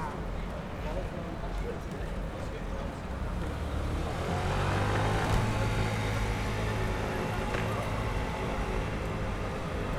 Environmental
Streetsounds
Noisepollution